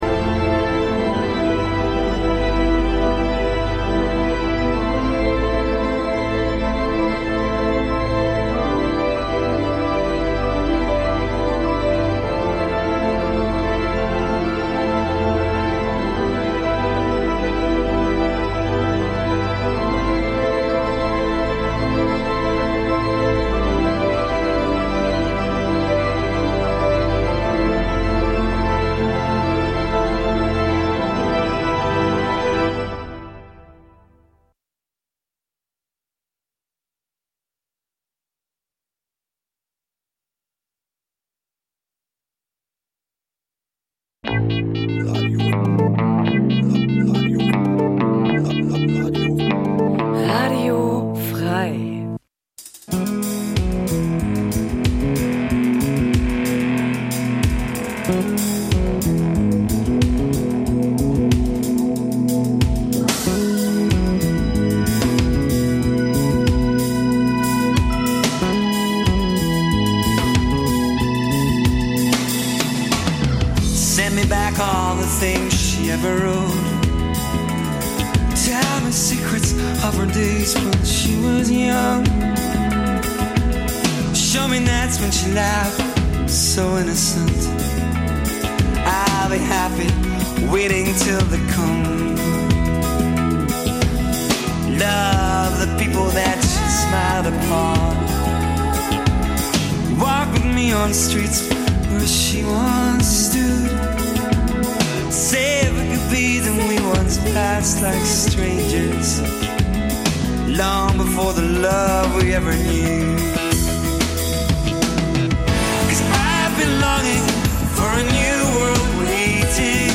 Im Vorfeld der regul�ren Partyzone h�ren wir alle 4 Wochen Interviews DJs, Veranstaltungen des Abends und �hnliches